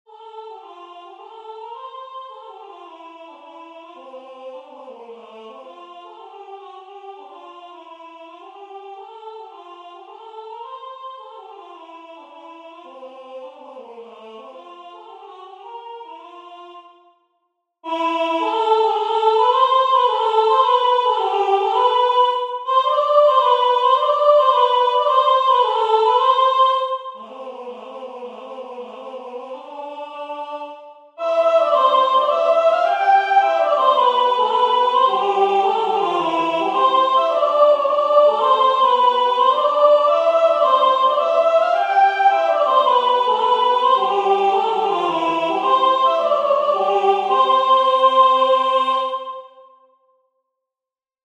Soprano Alto Tenor Bass